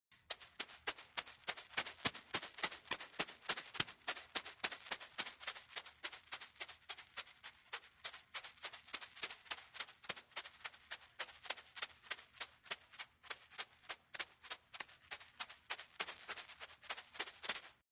Коллекция включает разные варианты: от мягких перезвонов до более ритмичных звуков.
Звук детской погремушки в руке при тряске